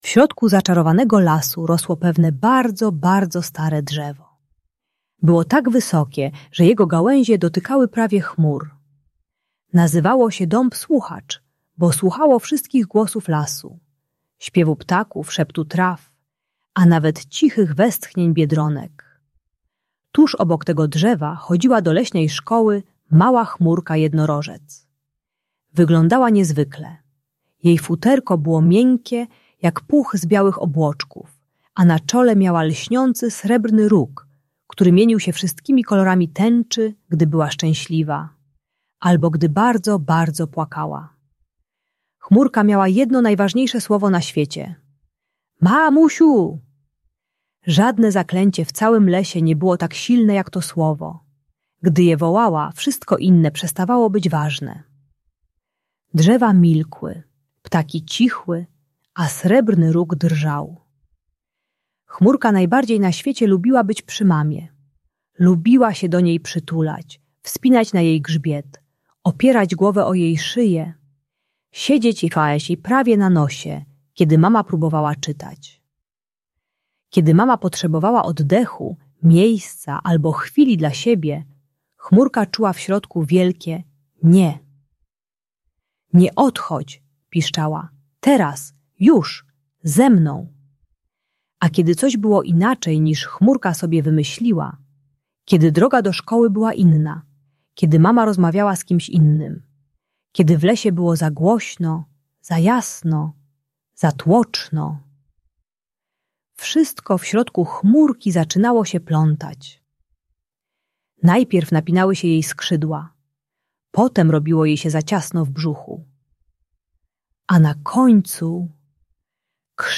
Zaczarowana historia Chmurki Jednorożca - Przywiązanie do matki | Audiobajka